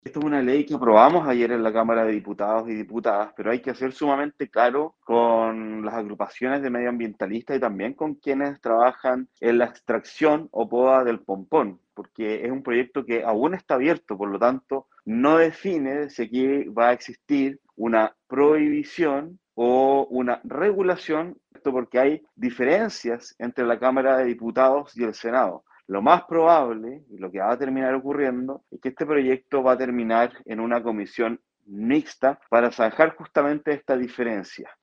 En tanto, el Diputado Liberal por el distrito 26, Alejandro Bernales, indicó que a su juicio el debate legislativo continuará y no está zanjado aún ya que serán revisados cada uno de los artículos en el Senado.